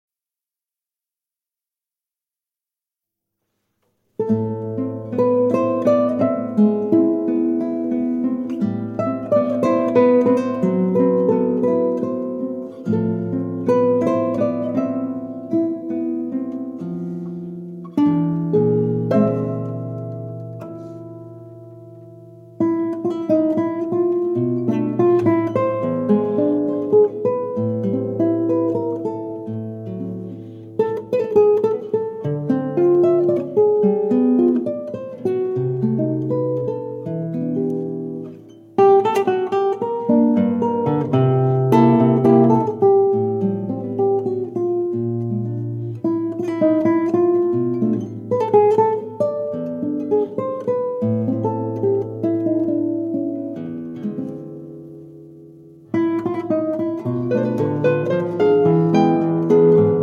Duo works from South America
Guitar